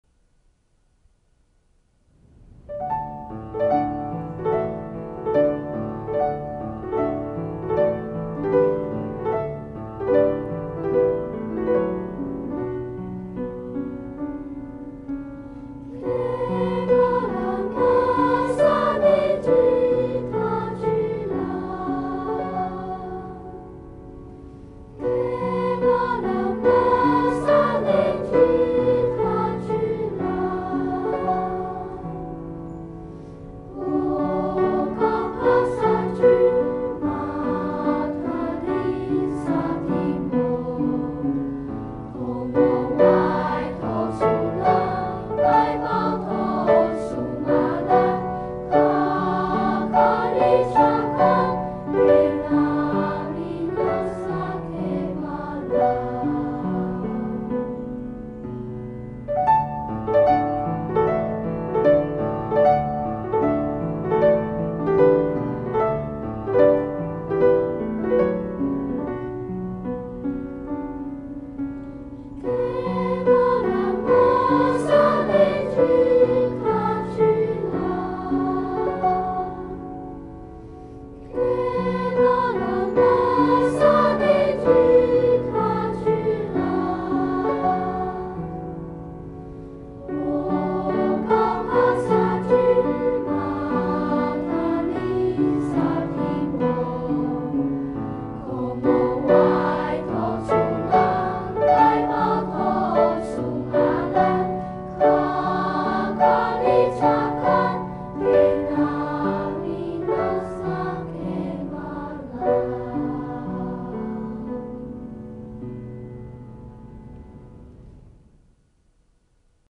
這些保存下來的祭歌，歌詞用語相當艱深，但是曲調都非常簡潔。